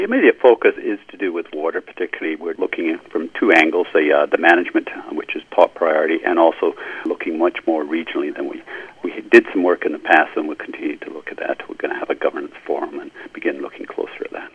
Goat News spoke with Bruce Jolliffe, CVRD board chair.